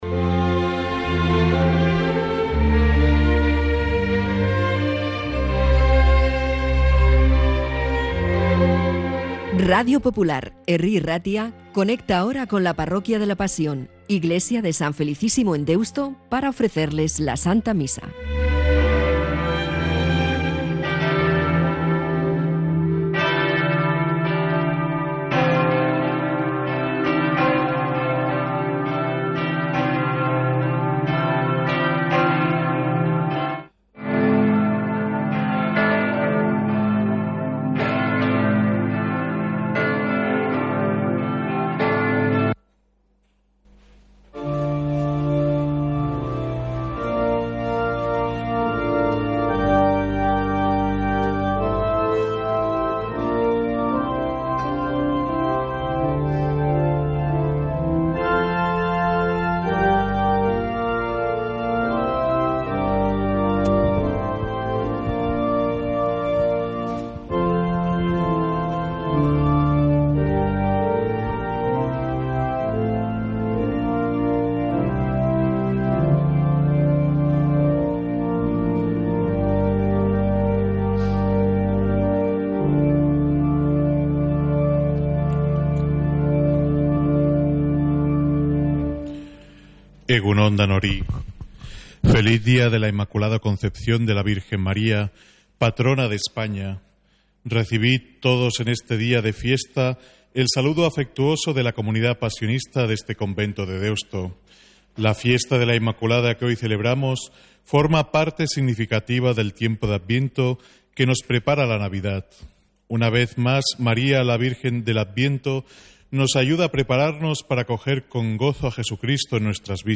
Santa Misa desde San Felicísimo en Deusto, domingo 8 de diciembre